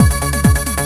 TECHNO125BPM 11.wav